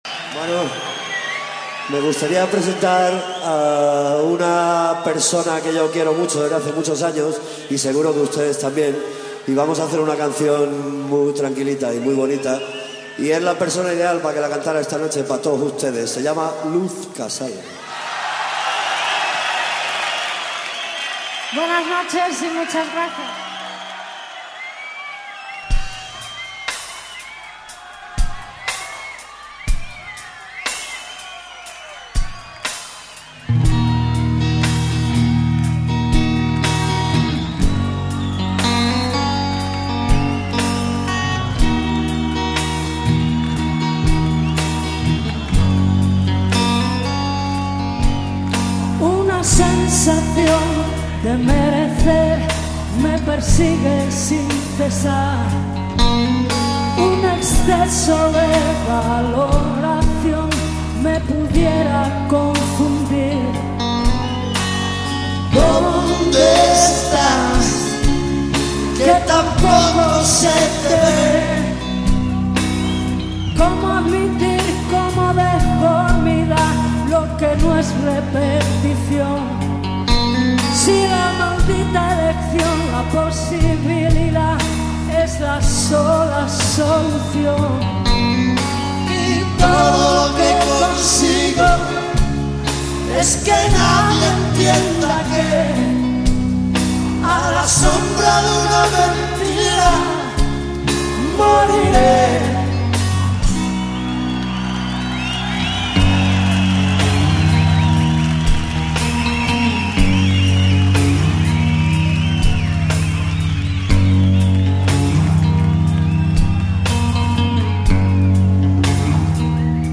guitarra_1 bajo voz_1